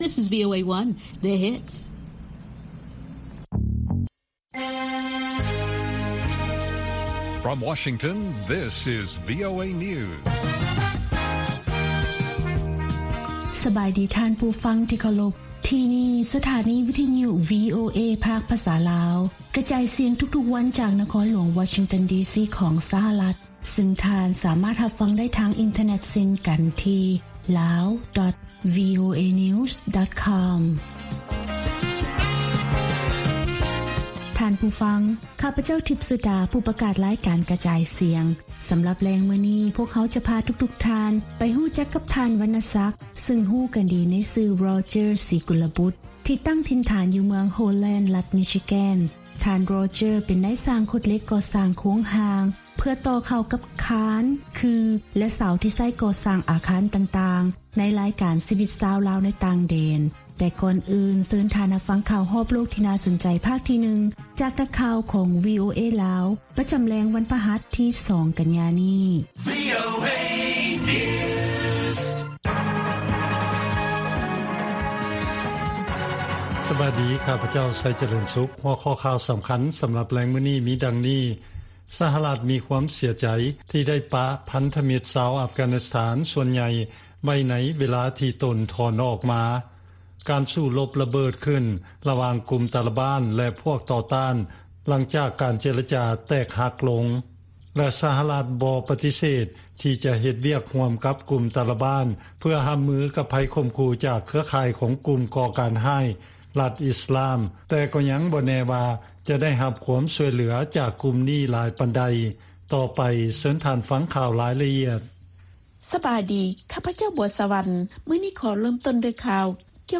ລາຍການກະຈາຍສຽງຂອງວີໂອເອ ລາວ: ນັກປັນຍາຊົນລາວ ຄິດວ່າ ການເຂົ້າໄປພົວພັນຂອງ ສຫລ ໃນຂົງເຂດເອເຊຍຕາເວັນອອກສຽງໃຕ້ຫລາຍຂຶ້ນຈະມີຜົນດີຕໍ່ຂົງເຂດ
ວີໂອເອພາກພາສາລາວ ກະຈາຍສຽງທຸກໆວັນ.